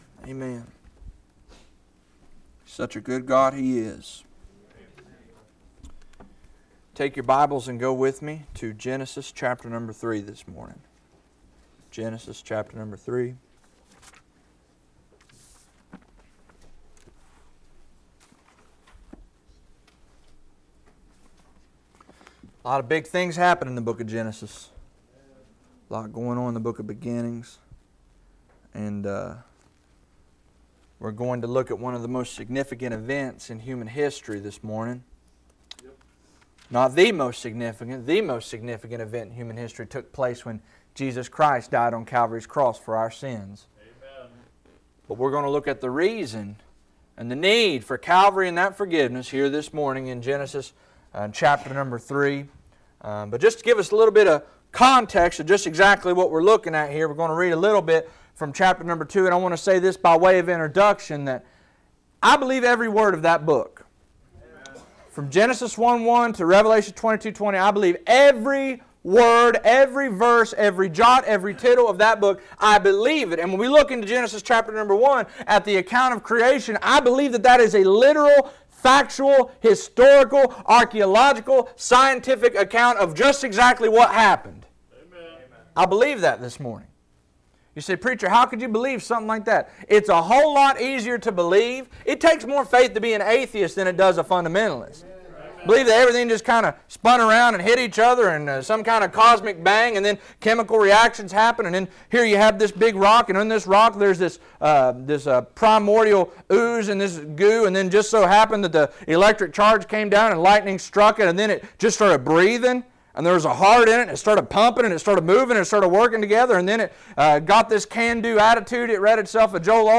Sermon audio is available below!